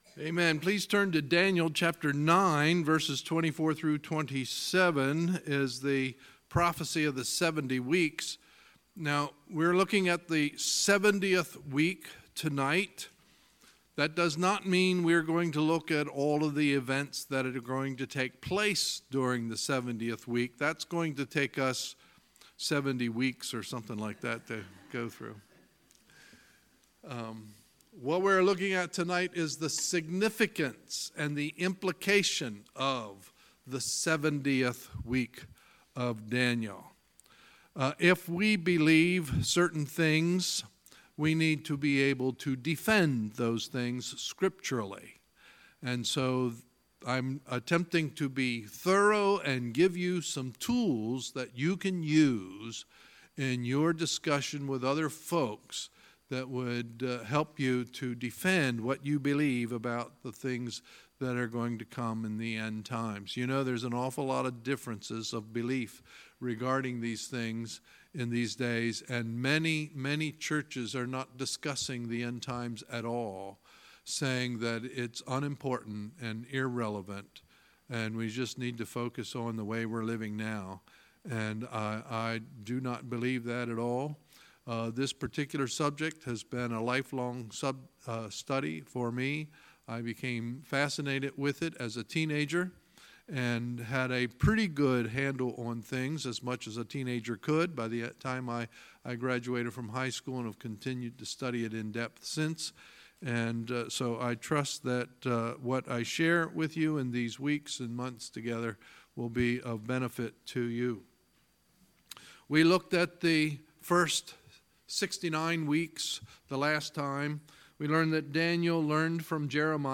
Sunday, September 30, 2018 – Sunday Evening Service